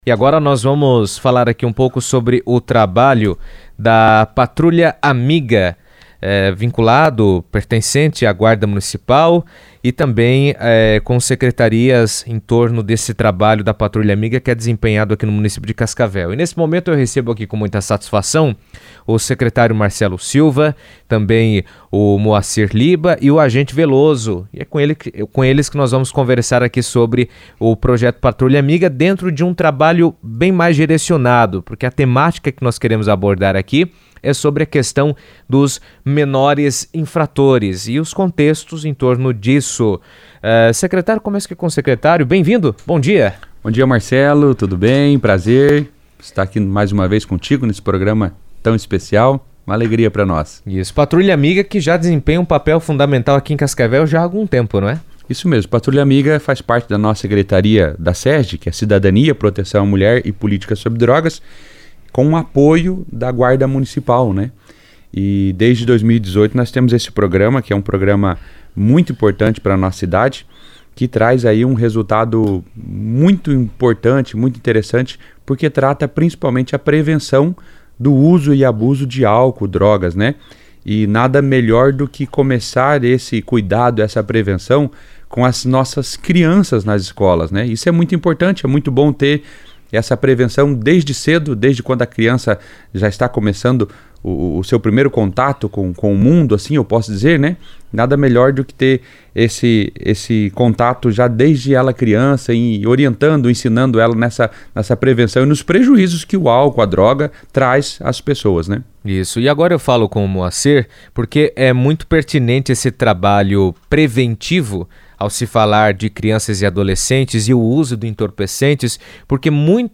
O programa Patrulha Amiga reforça ações voltadas à prevenção da criminalidade entre crianças e adolescentes, destacando os fatores que podem levar ao envolvimento com atos ilícitos e as estratégias para evitar esse desvio de conduta. O programa enfatiza o papel da família, da comunidade escolar e do poder público na proteção e orientação dos jovens. Em entrevista à CBN